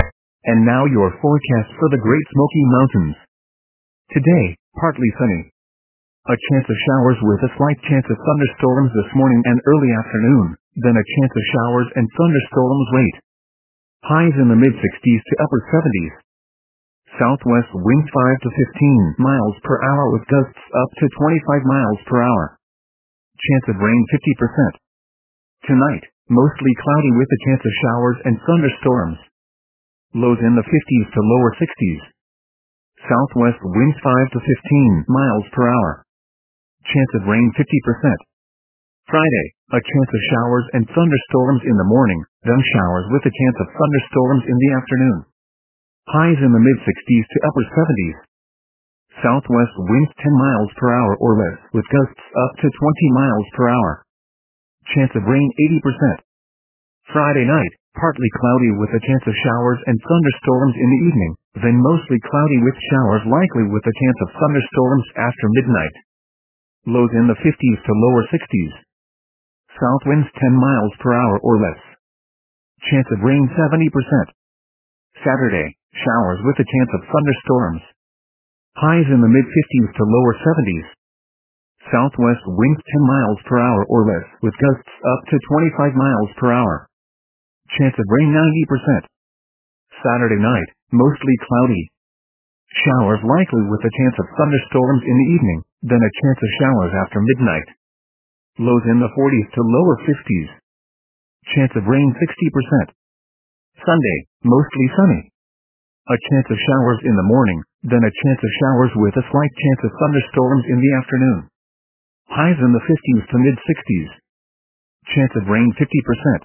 MRX Weather Radio Forecasts